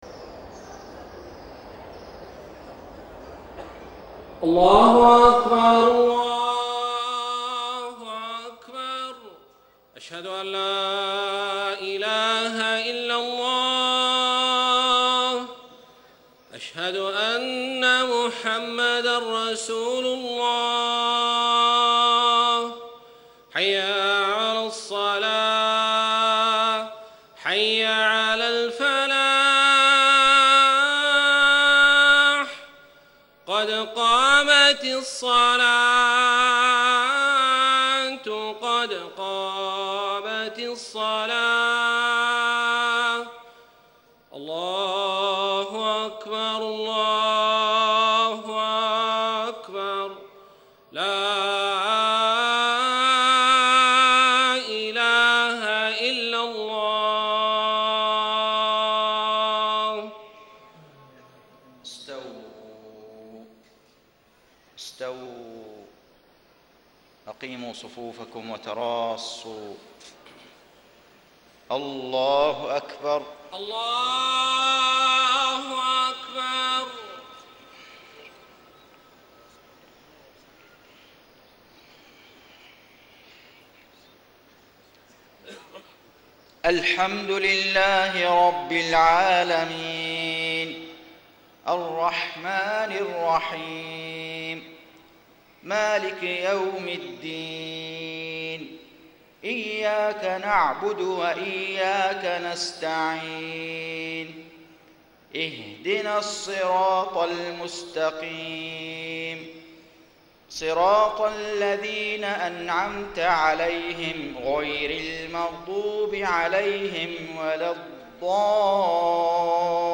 صلاة المغرب 6-5-1435 ما تيسر من سورة غافر > 1435 🕋 > الفروض - تلاوات الحرمين